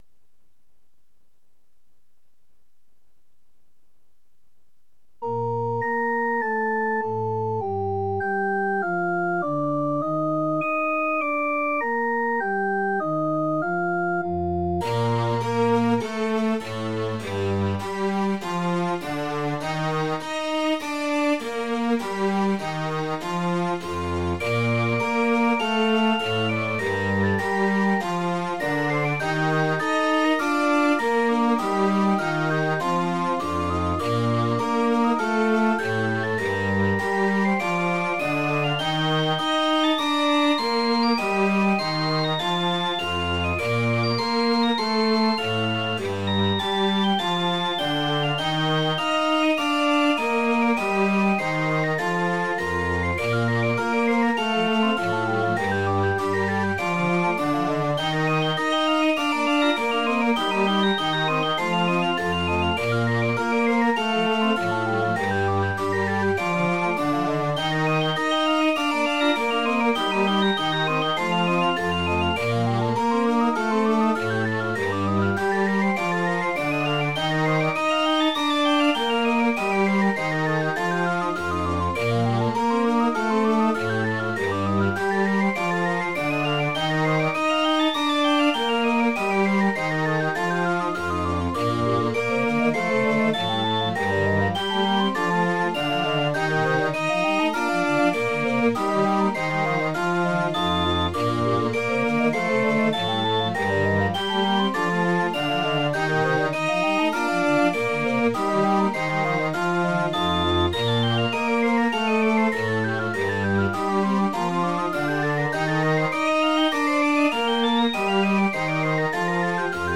Tweede deel (Andante larghetto) uit Organ Concerto opus 7 no. 5 (5:05)